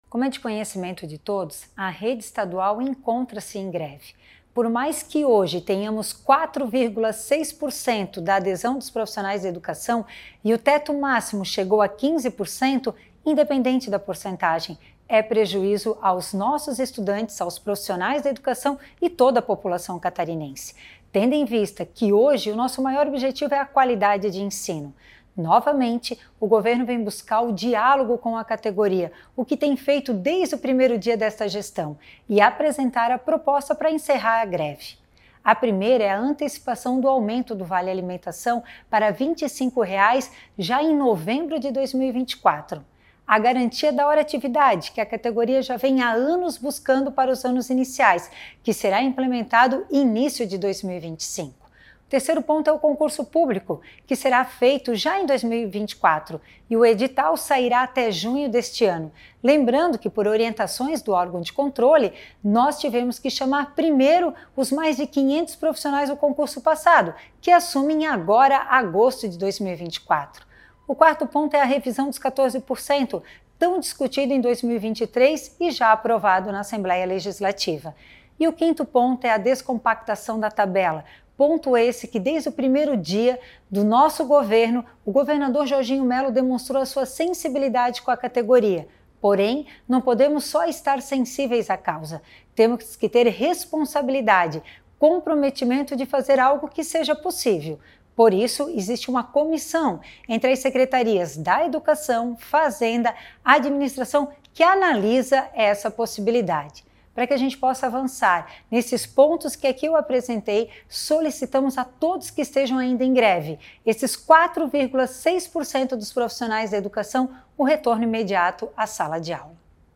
Segundo a secretária adjunta da educação, Patrícia Lueders, a preocupação maior é evitar prejuízos para a categoria como um todo.